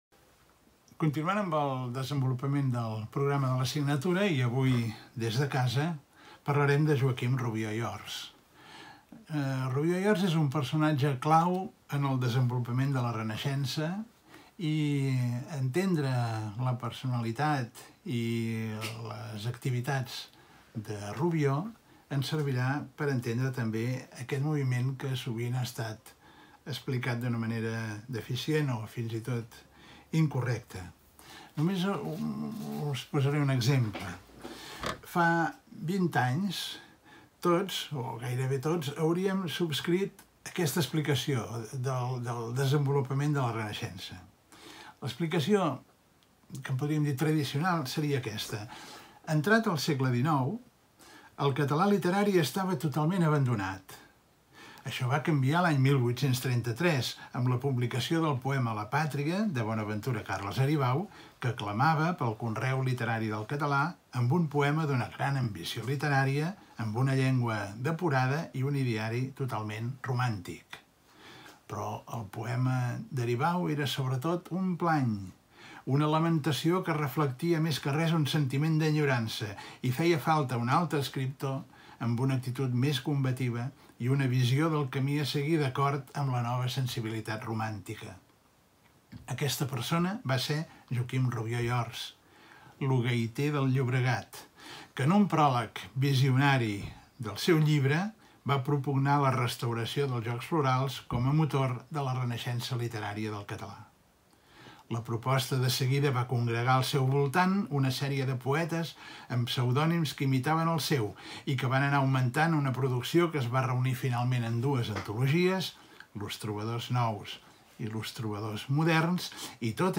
Masterclass sobre Joaquim Rubió
Classe magistral